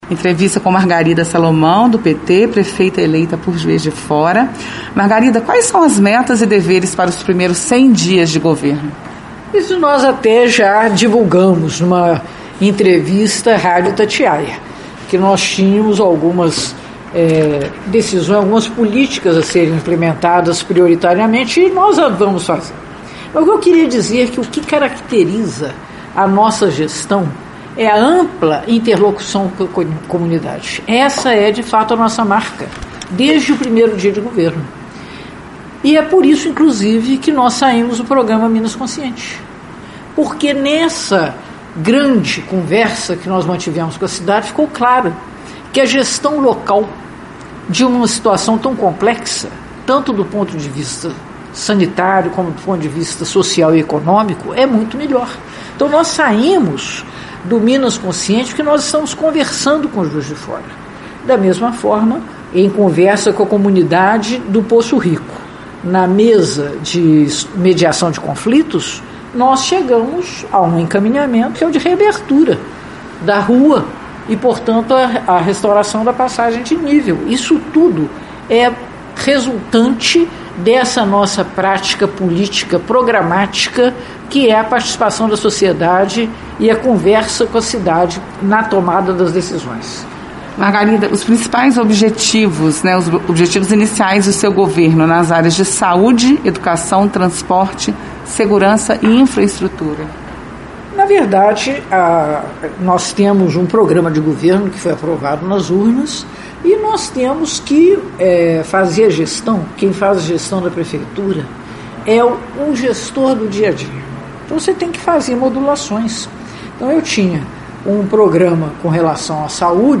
juiz-de-fora-entrevista-Margarida-2.mp3